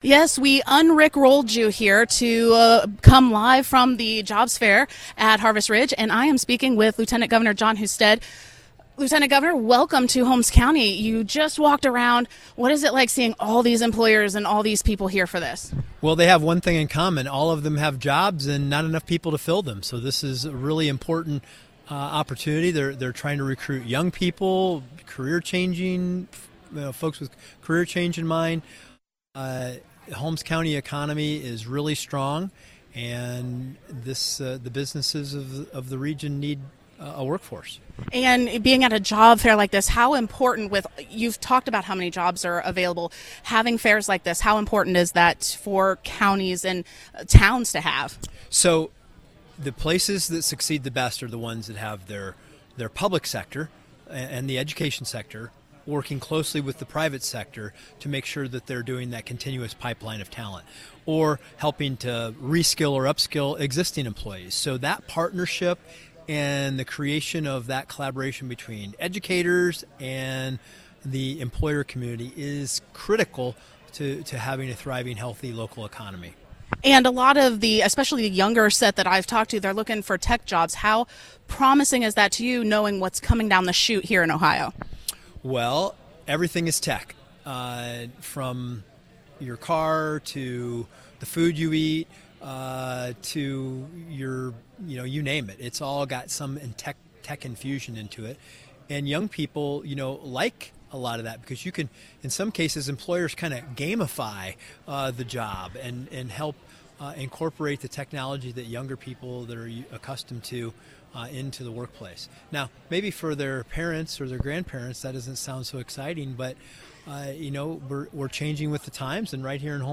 4-30-24 Interview with Lt Governor Jon Husted